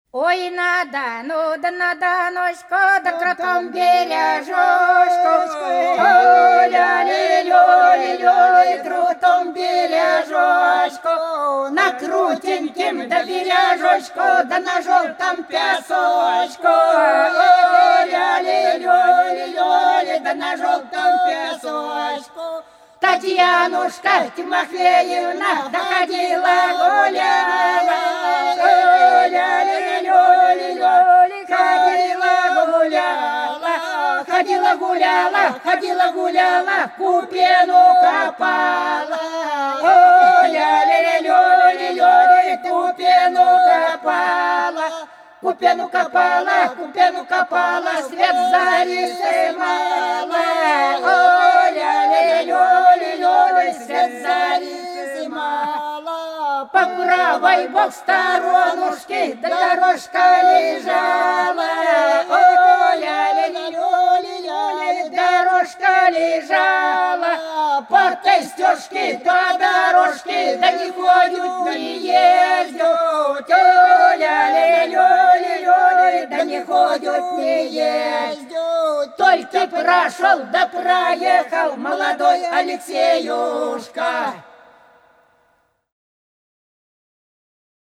По-над садом, садом дорожка лежала Ой на Дону, на Доночку - плясовая (с.Плёхово, Курская область)
11_Ой_на_Дону,_на_Доночку_(плясовая).mp3